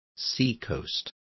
Complete with pronunciation of the translation of seacoast.